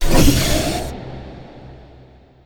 takeoff.wav